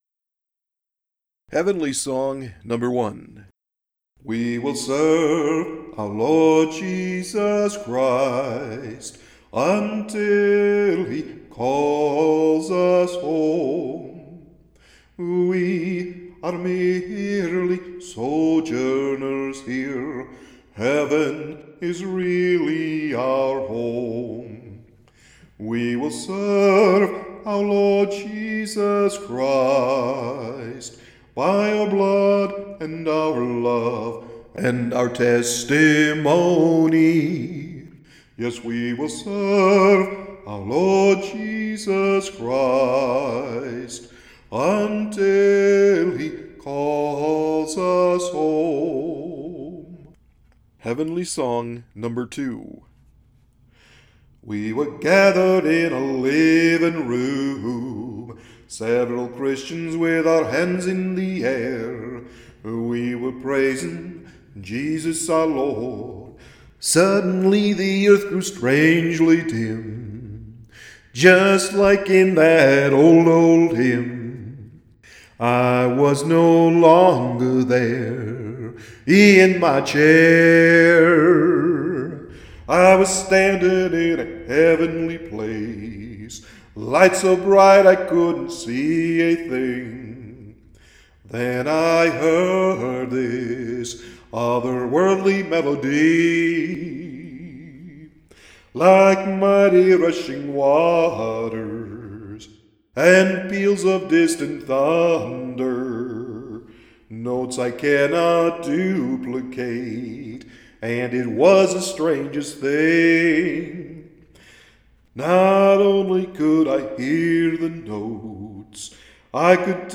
was more of a modern song